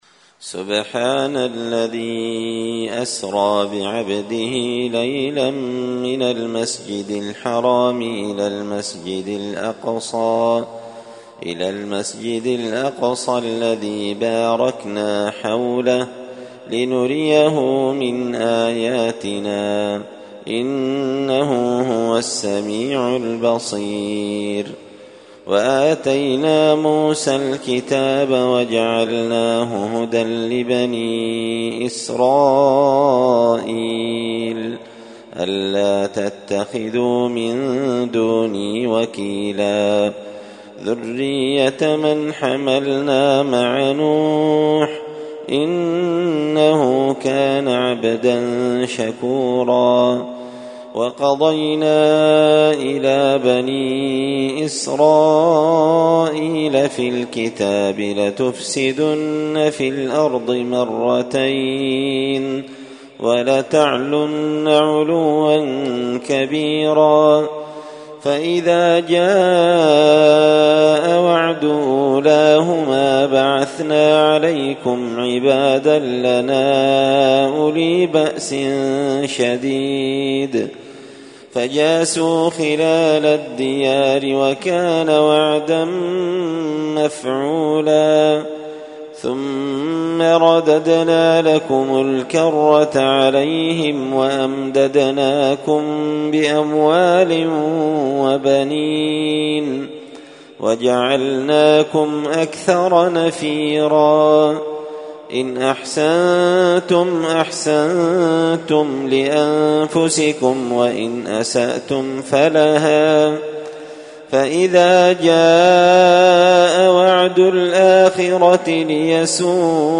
تلاوة من أول سورة الإسراء
الأحد 29 ذو القعدة 1444 هــــ | قران كريم | شارك بتعليقك | 23 المشاهدات
تلاوة-من-أول-الإسراء.mp3